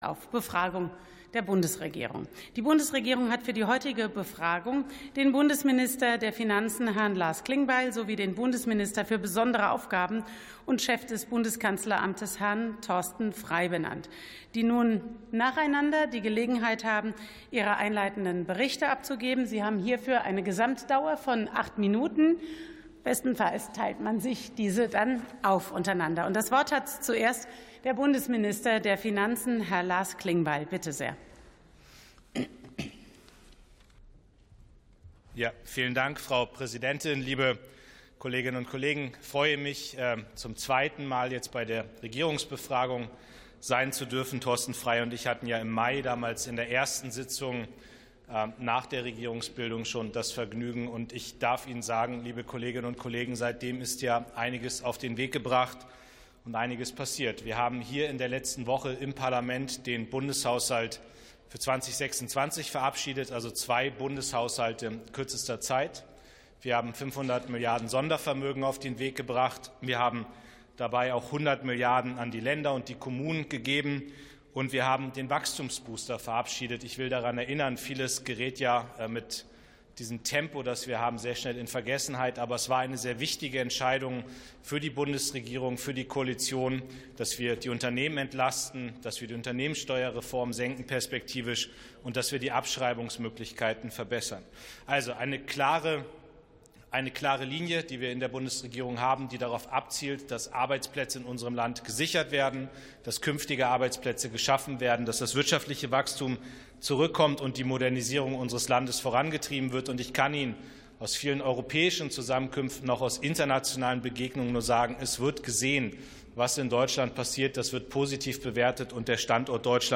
Befragung der Bundesregierung (BMF und BKAmt)